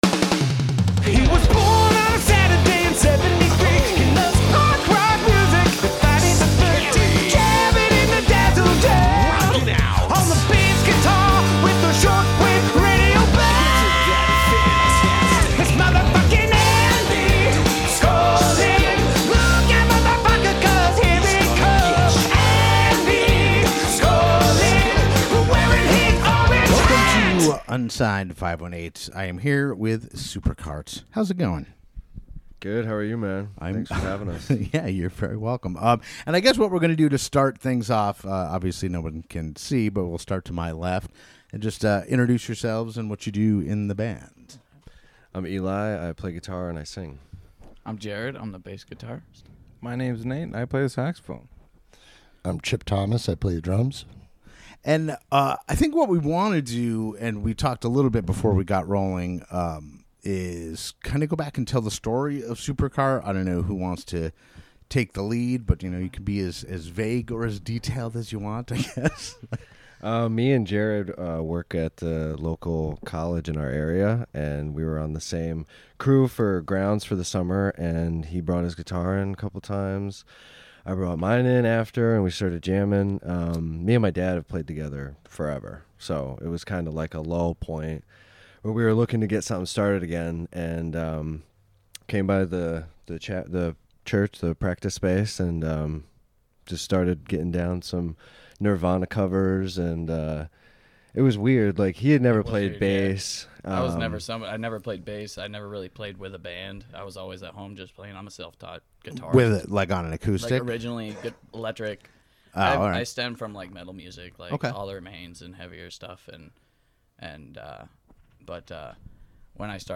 On this episode, the boys from SuperKart stopped by the Dazzle Den to discuss their origin story, including how quickly they went from playing some covers to writing more than a dozen killer original tunes.